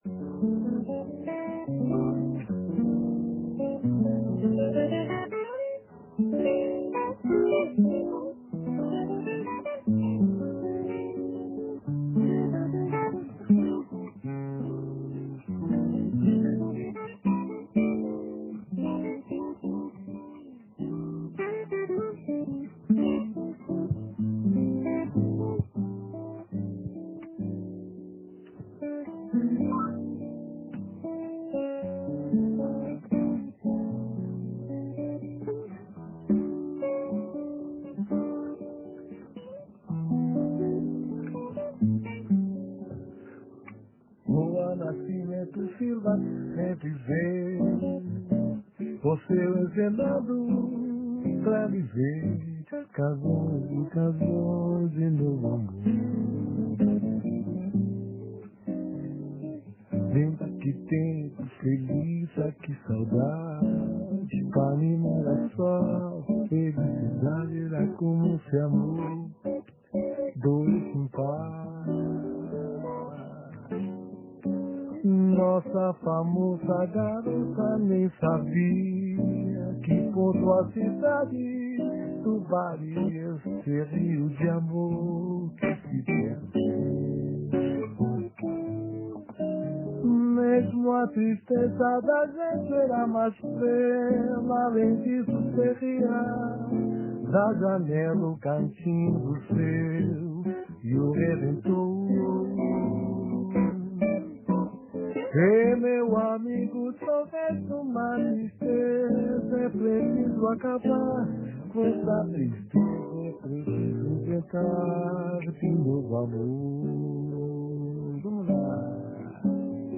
se presentó en los estudios de El Espectador sorpresivamente
interpretaron algunos temas para los cocafeteros